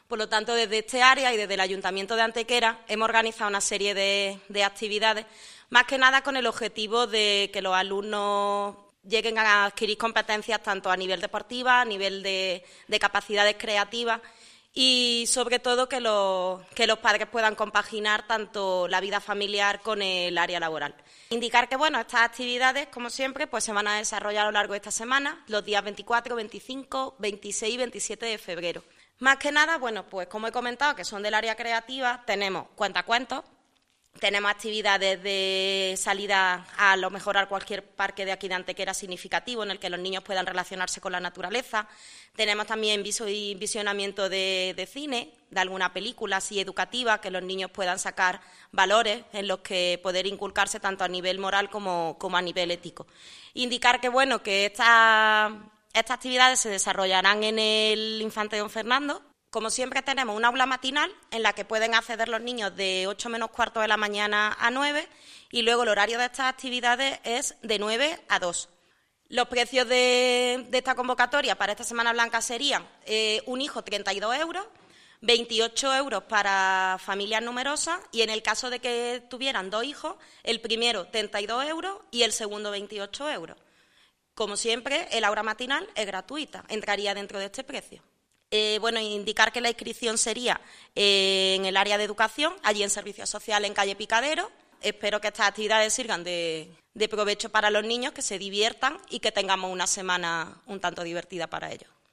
La concejal delegada de Familia, Equidad y Educación, Sara Ríos, ha presentado hoy en rueda de prensa el ya tradicional Campamento de Semana Blanca, actividad promovida por el Ayuntamiento de Antequera y desarrollada a través de la empresa especializada Edukaria con el objetivo de facilitar la conciliación de la vida familiar y laboral durante la época no lectiva de la Semana Blanca
Cortes de voz